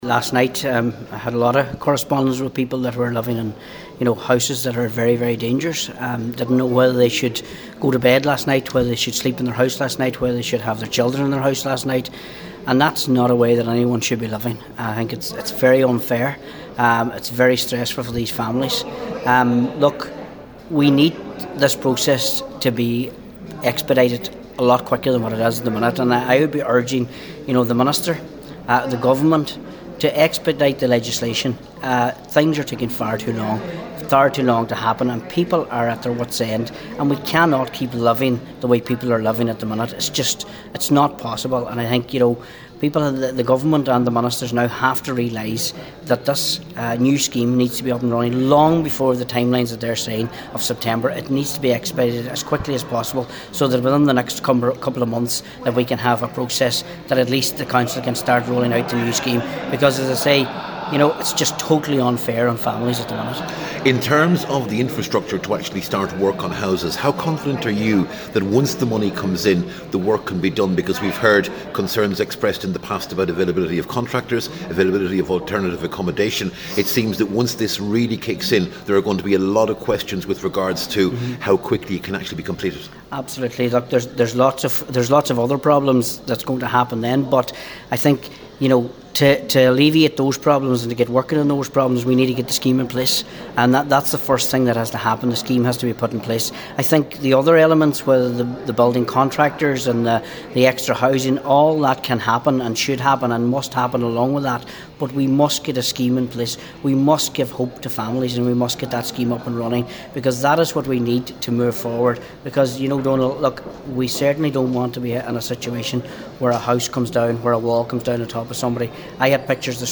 Calling for the implementation of the revised scheme to be fast tracked, he told Highland Radio News if government officials don’t stop dragging their heels, the consequences could be disastrous: